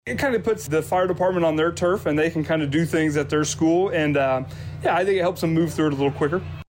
Firefighter